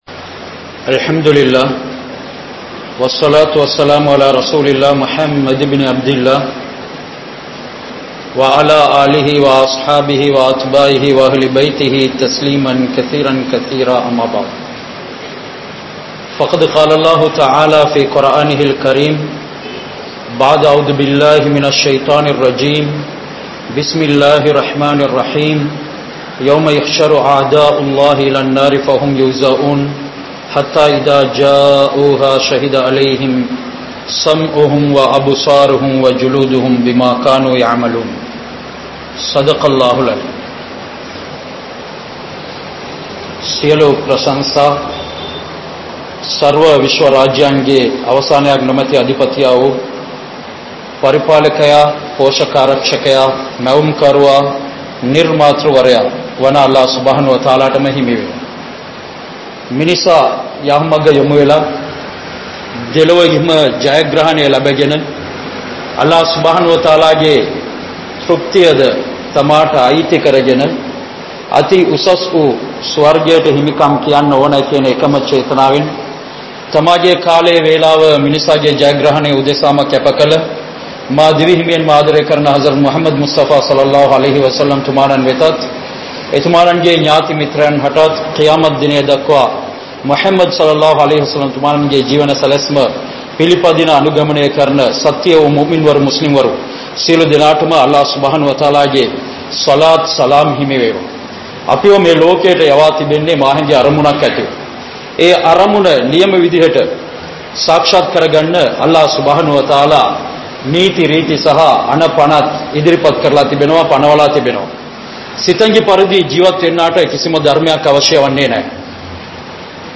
Unmaiyaana Veattri Ethu? (உண்மையான வெற்றி எது?) | Audio Bayans | All Ceylon Muslim Youth Community | Addalaichenai
Al Othaiby Jumuah Masjidh